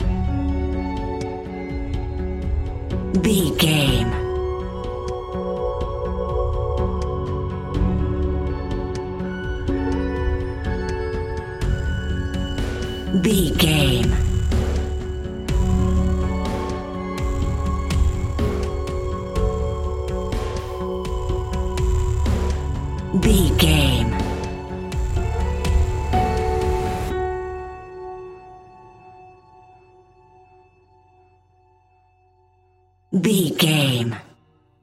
Ionian/Major
D♭
electronic
techno
trance
synths
synthwave
instrumentals